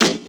Snare_05.wav